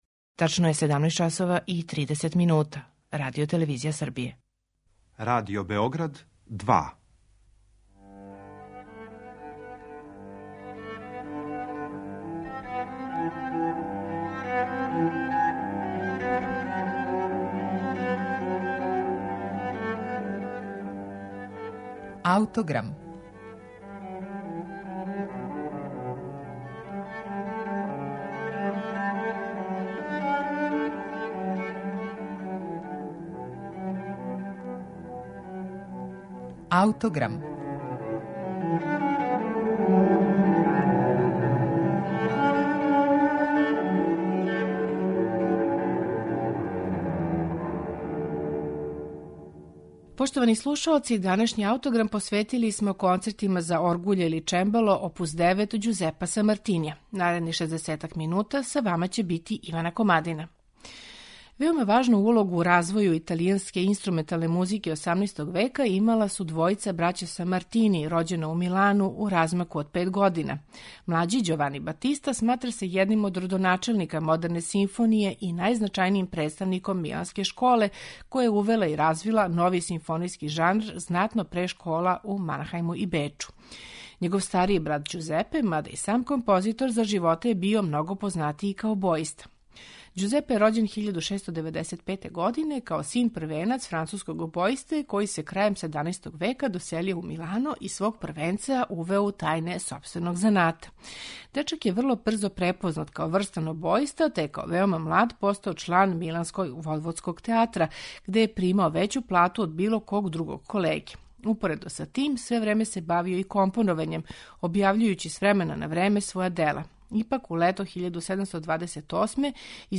Kонцерти за оргуље или чембало